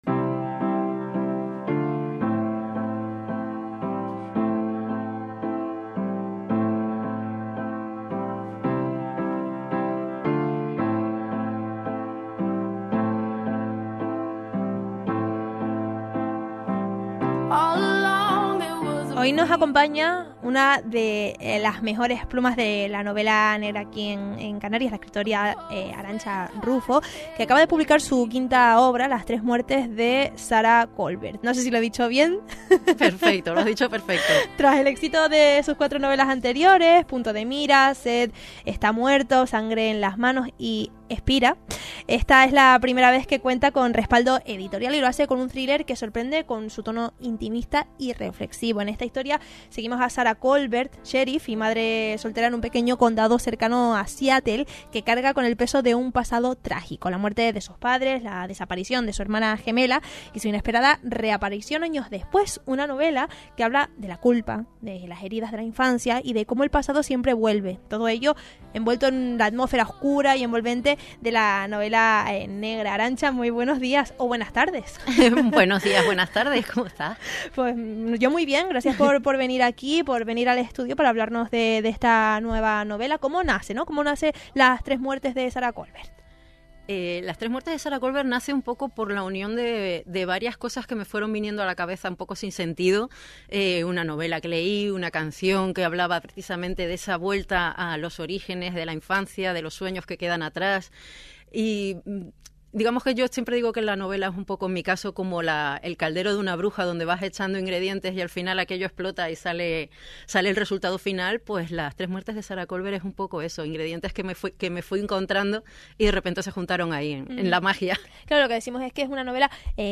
Entrevista en Hoy por Hoy, para SER Radio Club Tenerife